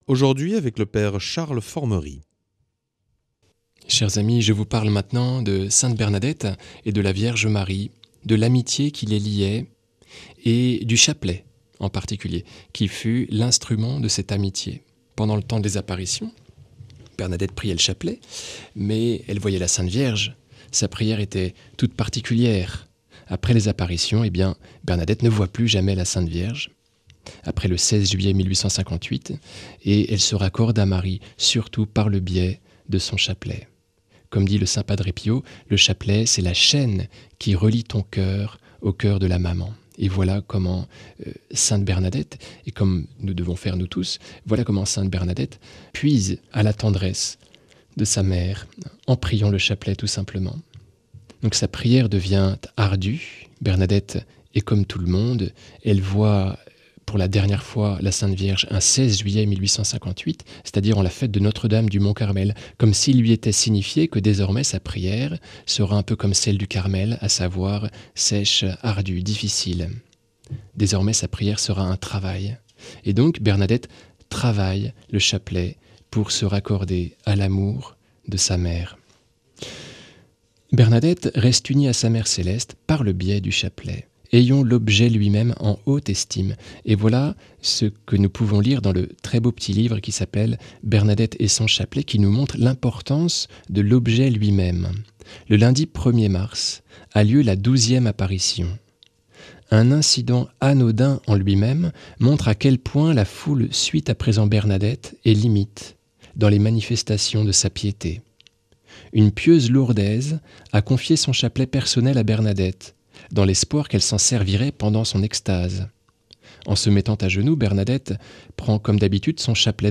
Enseignement Marial